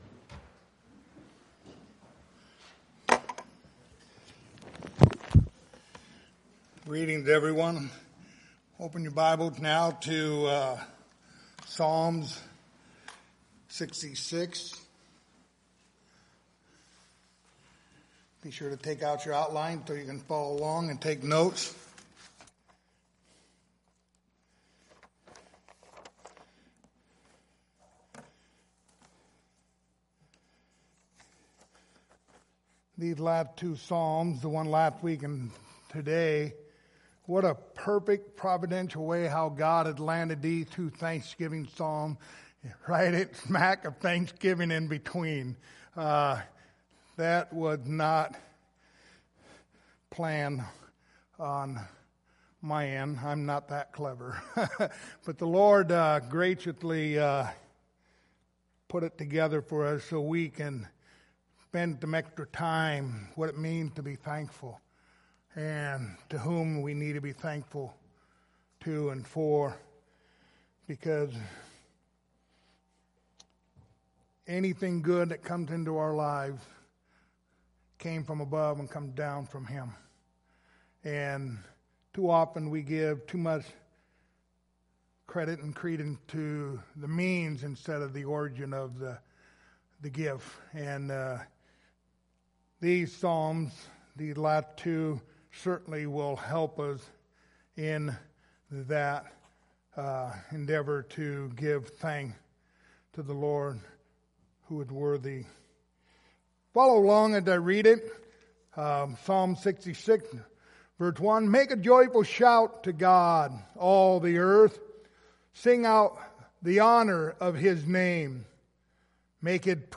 Passage: Psalm 66:1-20 Service Type: Sunday Morning